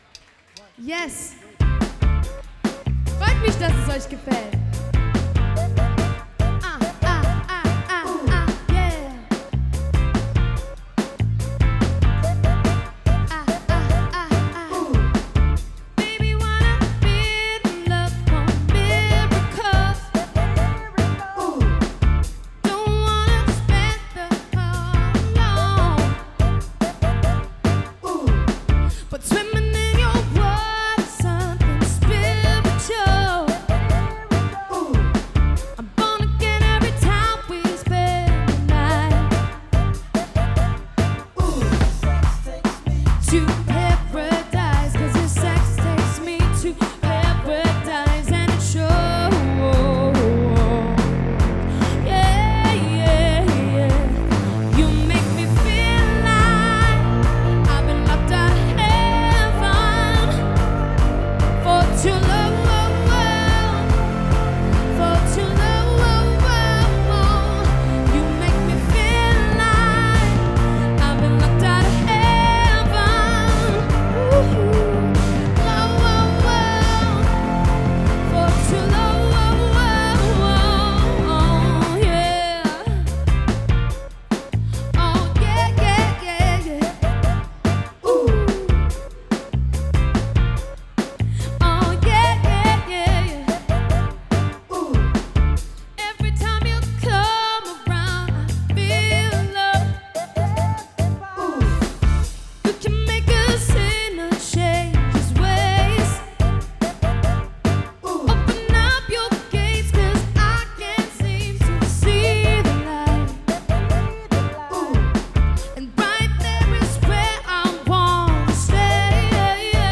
Sängerin